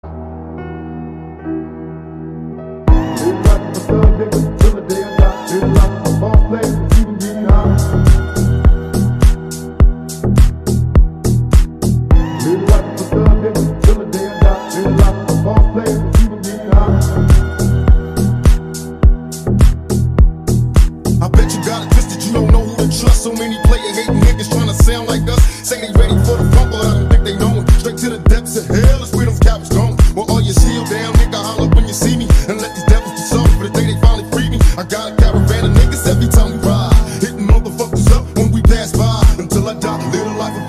Dzwonki na telefon
Kategorie Rap